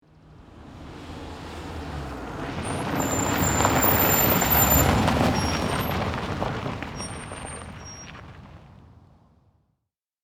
Car Braking, Tire Screech Sound Effect Download | Gfx Sounds
Car-braking-tire-screech.mp3